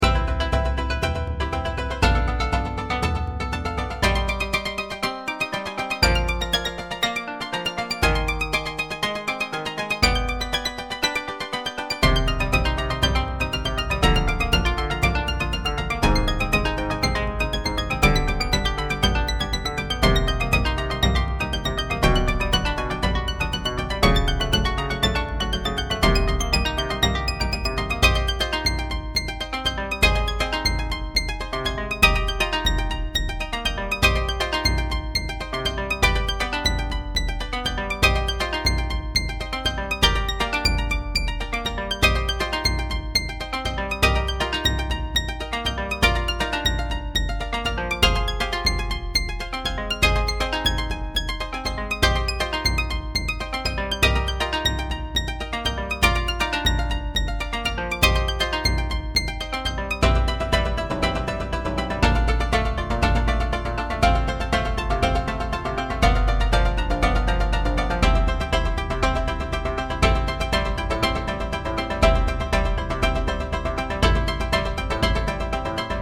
三味線、琴、太鼓 ※和楽器